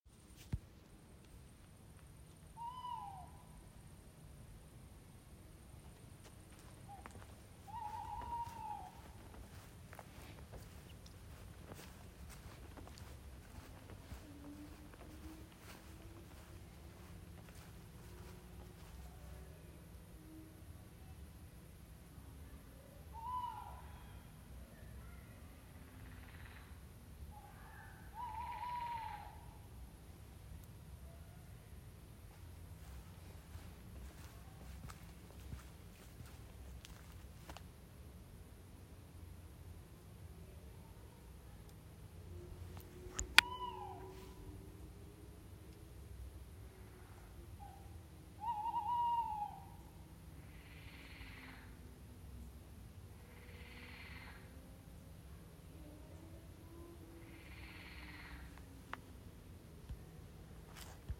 Iemand verstand van dierengeluiden? Elke avond zodra het donker is hoor ik dit sinds een paar weken, haha, echt raar toch? We wonen aan de rand van een bos.